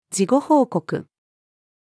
事後報告-female.mp3